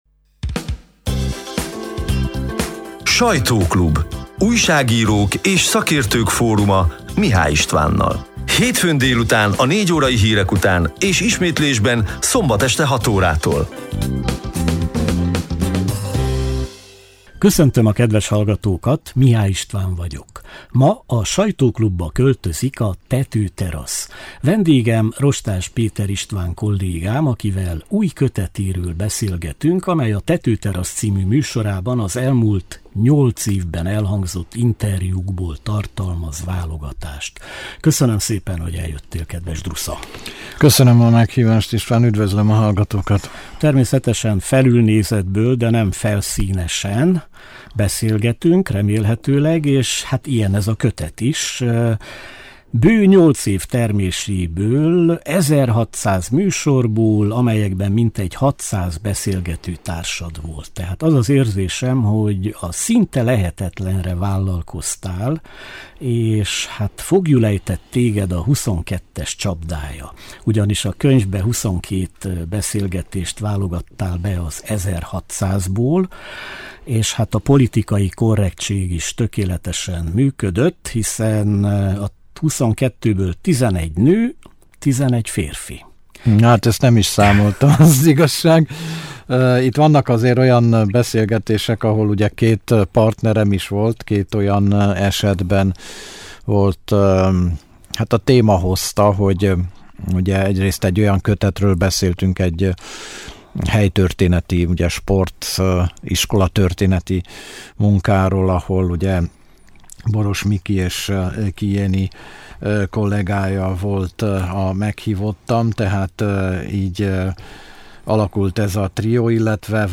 A lejátszóra kattintva a hétfő délutáni élő műsor kissé rövidített változatát hallgathatják meg.